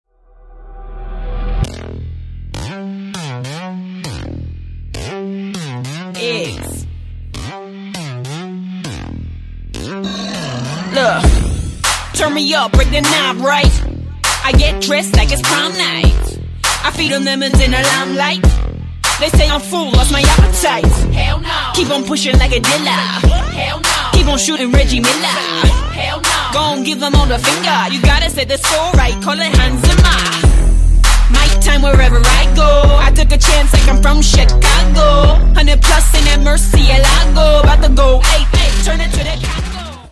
• Качество: 128, Stereo
громкие
басы
качающие
женский рэп
electro hop
Рэп рингтончик с женским вокалом.